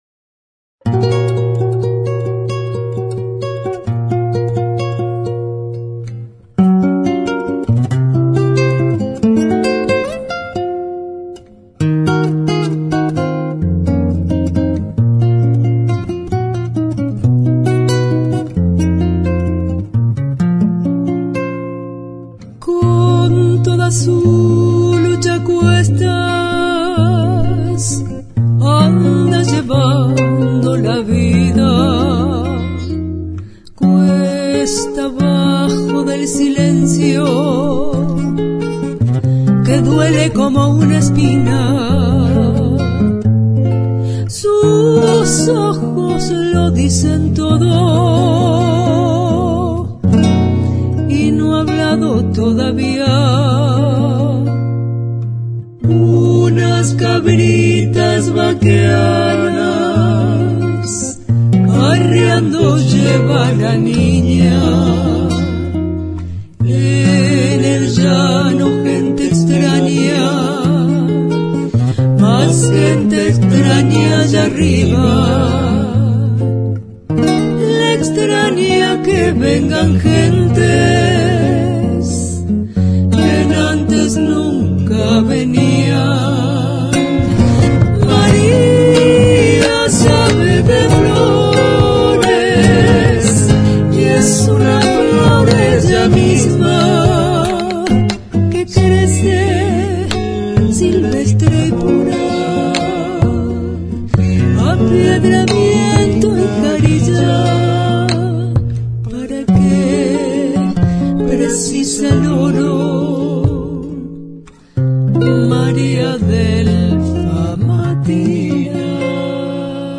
La zamba